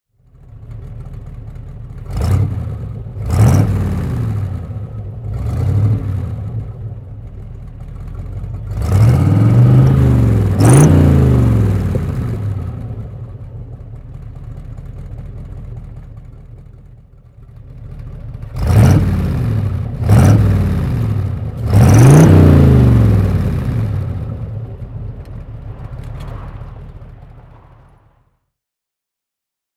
Motorsounds und Tonaufnahmen zu De Tomaso Fahrzeugen (zufällige Auswahl)
De Tomaso Pantera L (1974) - Leerlauf
De_Tomaso_Pantera_L_1974.mp3